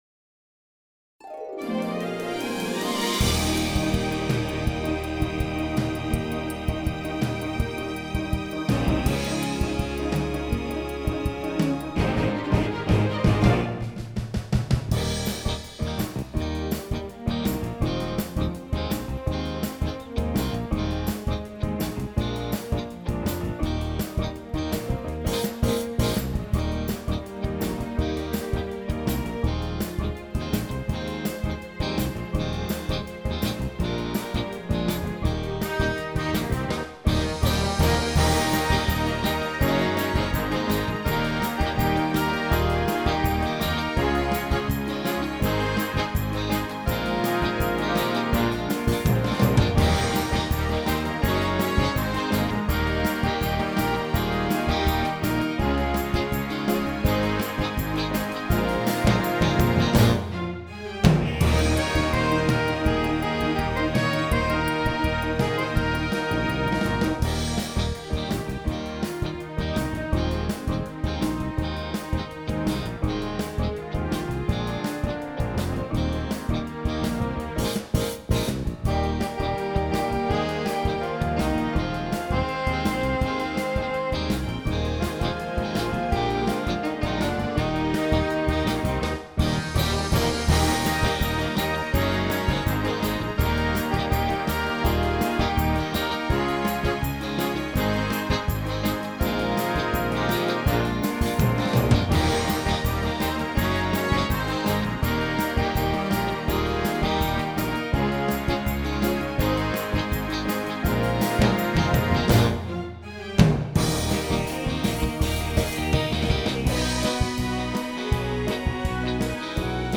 Genre: Musical.